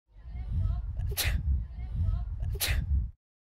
دانلود آهنگ عطسه 2 از افکت صوتی انسان و موجودات زنده
دانلود صدای عطسه 2 از ساعد نیوز با لینک مستقیم و کیفیت بالا
جلوه های صوتی